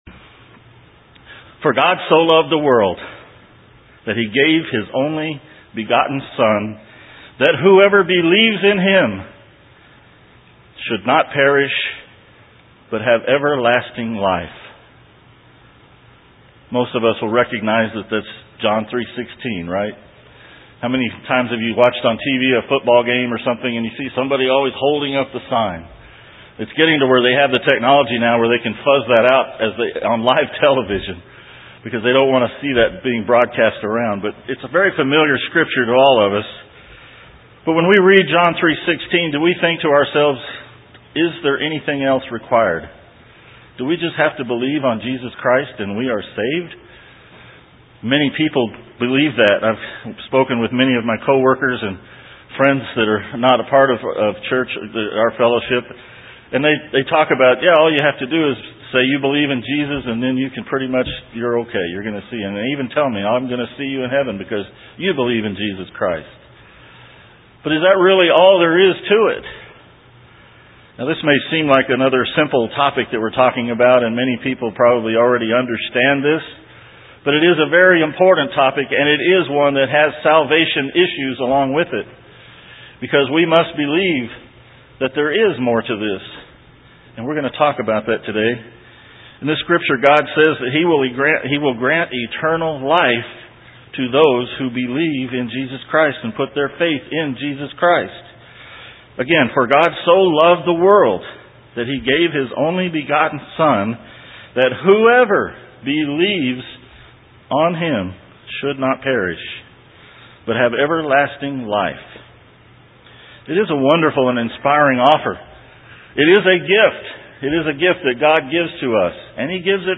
Sermons
Given in San Antonio, TX